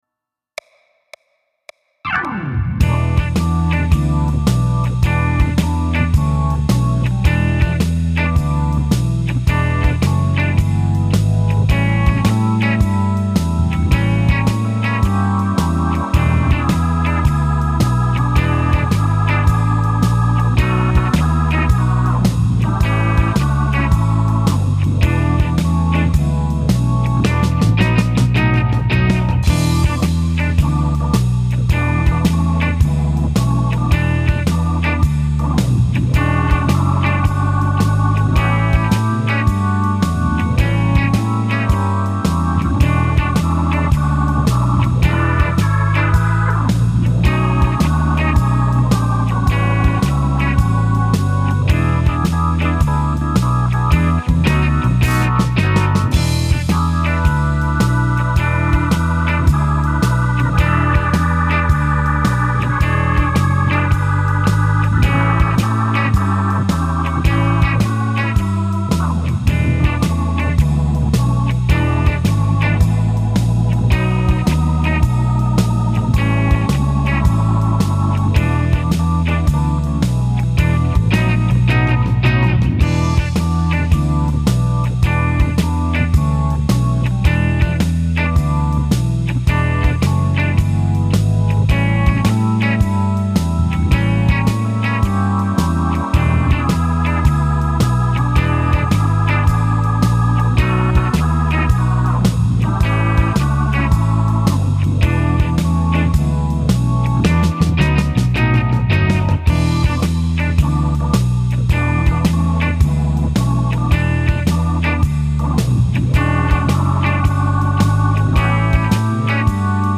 Blues in E